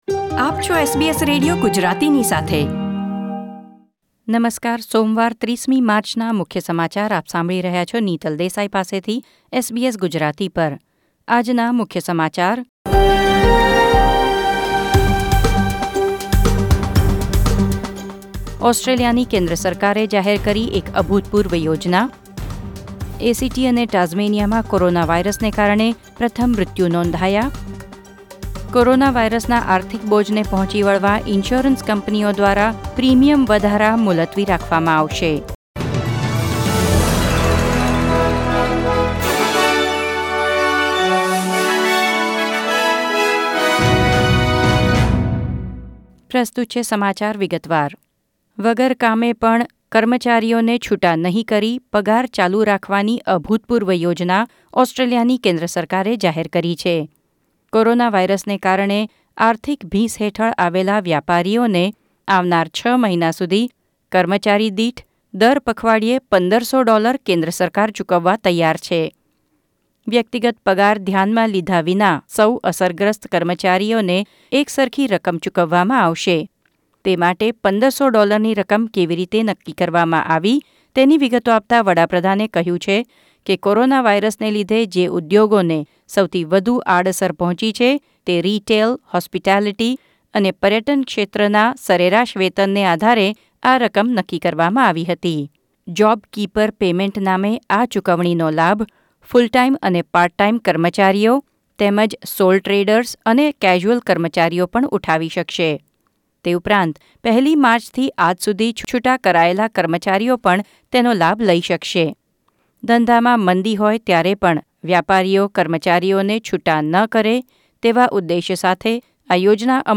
SBS Gujarati News Bulletin 30 March 2020